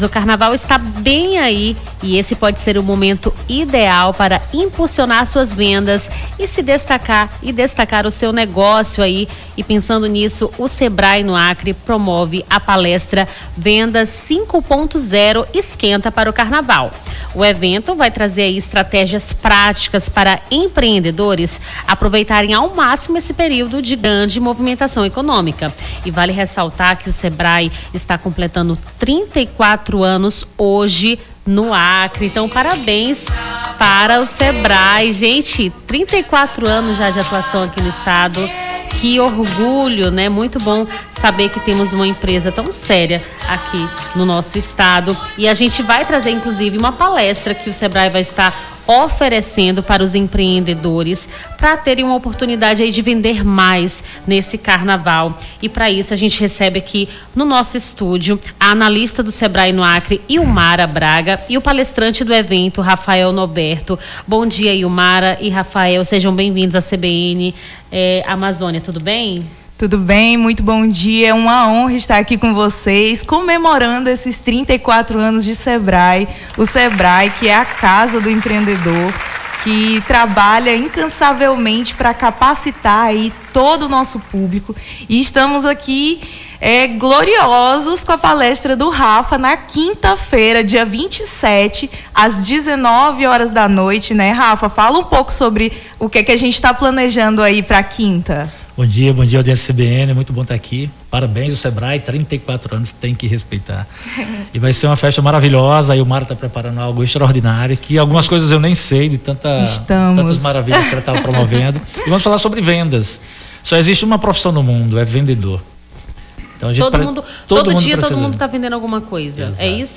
Nome do Artista - CENSURA - ENTREVISTA SEBRAE PALESTRA AUMENTO DE VENDAS NO CARNAVAL (25-02-25).mp3